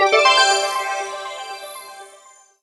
get_pickup_01.wav